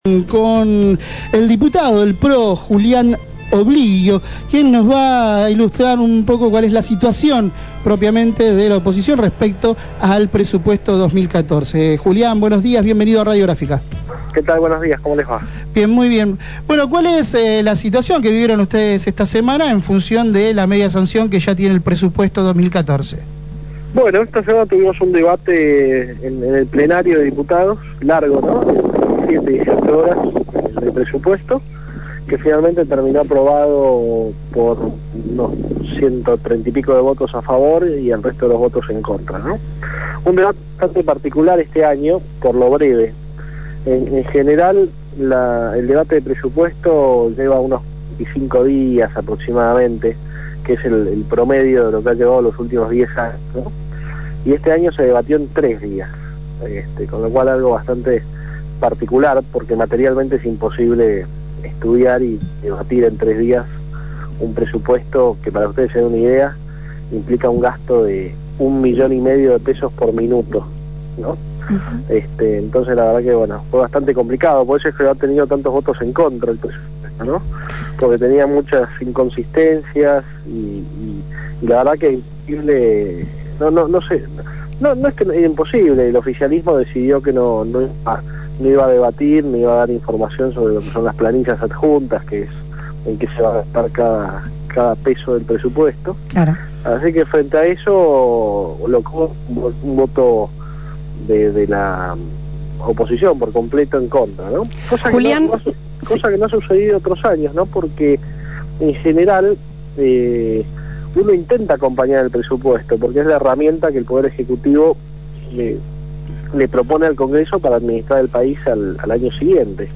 En diálogo con Feos, Sucios y Malas, criticó la celeridad con la que se debatió la ley de Presupuesto 2014, que recibió media sanción la semana pasada con los votos del Frente para la Victoria y los partidos aliados.